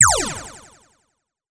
powerChangeObjectsPosition.wav